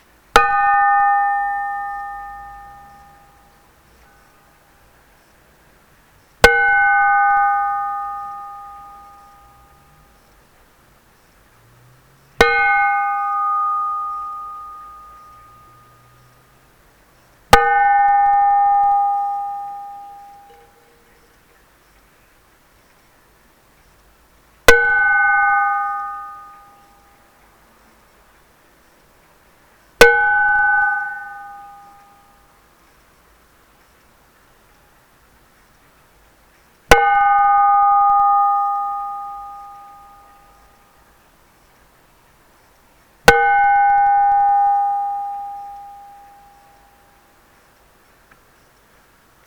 Frying Pan
ding frying-pan kitchen percussion sound effect free sound royalty free Sound Effects